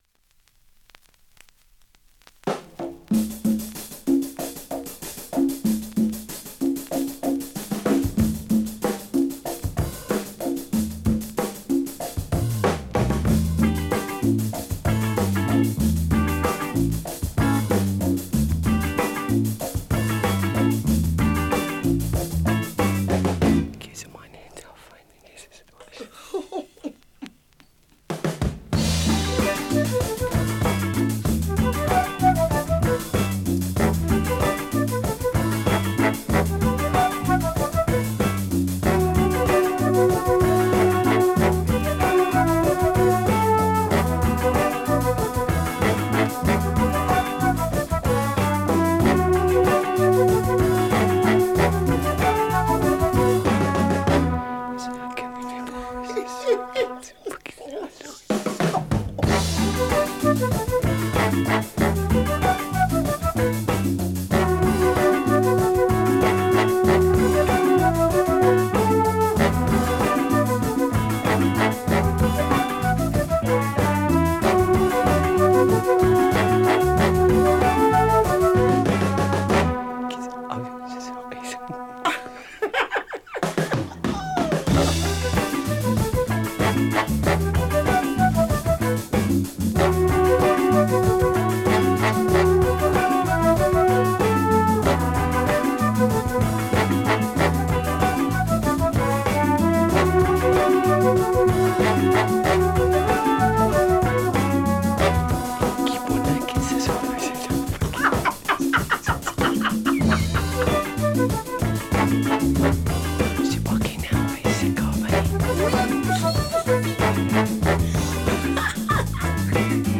◆盤質両面/ほぼEX+